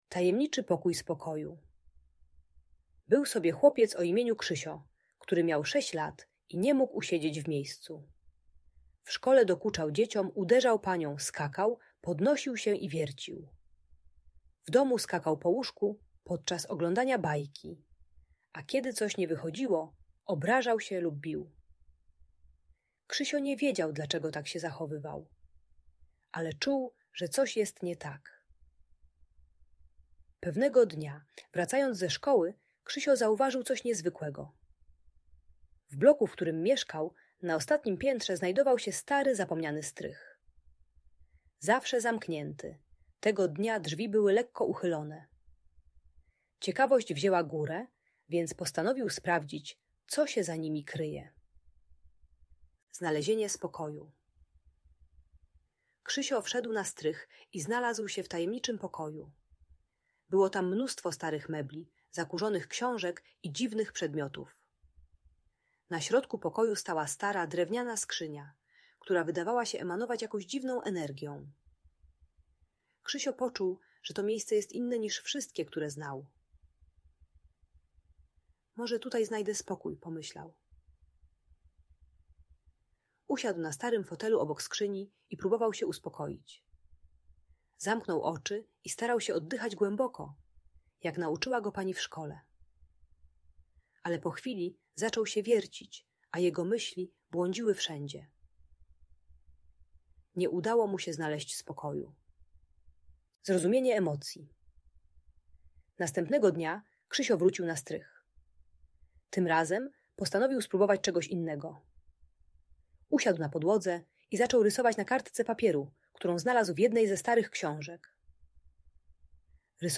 Tajemniczy Pokój Spokoju - story o znalezieniu spokoju - Audiobajka dla dzieci